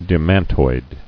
[de·man·toid]